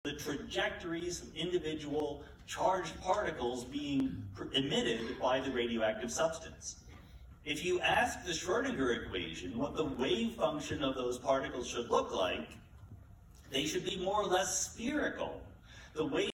1. Sean Carroll lecture
Sean Carroll The lecture ``Something Deeply Hidden: Quantum Worlds and the Emergence of Spacetime" organized by the Harvard book store, the division of science and the science library was a packed lecture in Hall C. Even the stairs and side walls were filled with people. Carroll is an excellent speaker.